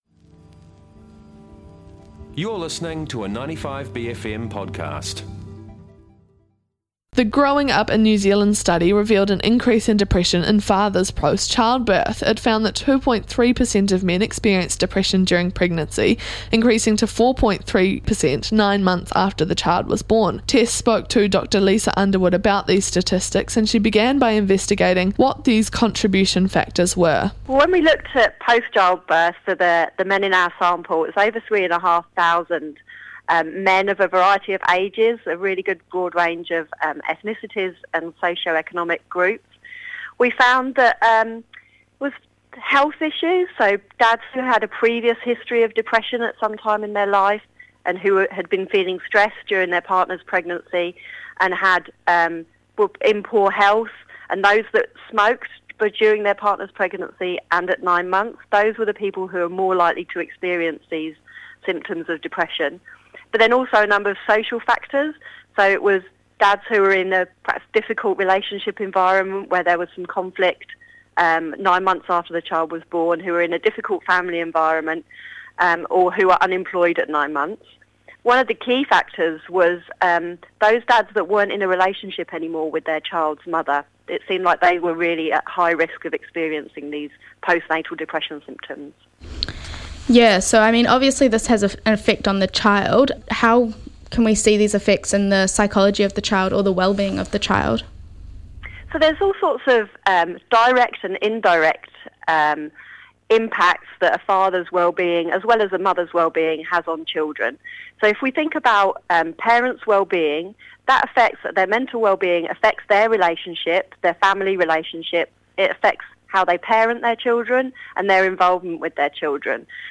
The Growing up in New Zealand study revealed an increase in depression in fathers post childbirth. It found that 2.3 per cent of men experienced depression during pregnancy increasing to 4.3 per cent nine months after the child was born. bFM reporter